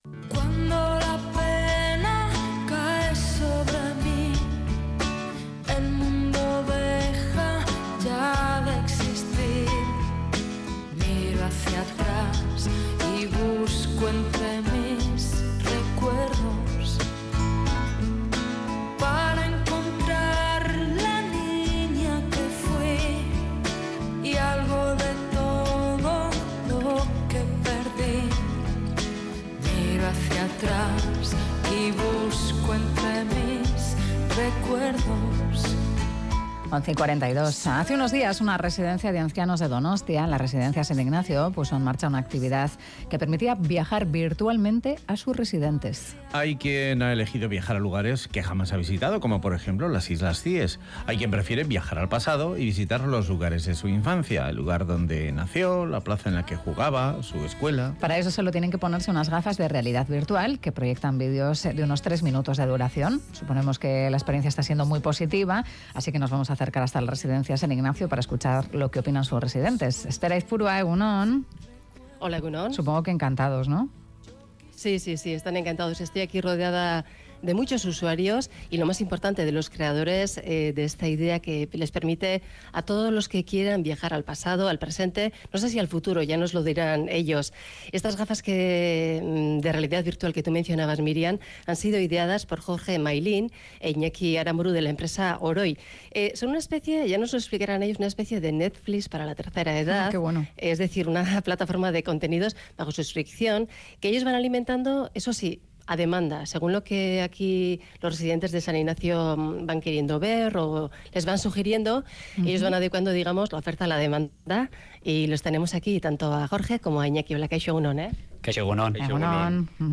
Radio Euskadi BOULEVARD Viajamos virtualmente de la mano de los residentes de San Ignacio Última actualización: 12/06/2018 12:47 (UTC+2) Nos acercamos a la residencia San Ignacio, para escuchar las emociones de quienes están utilizando las gafas de realidad virtual que la empresa OROI ha ideado para ellos.